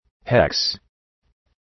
Shkrimi fonetik {heks}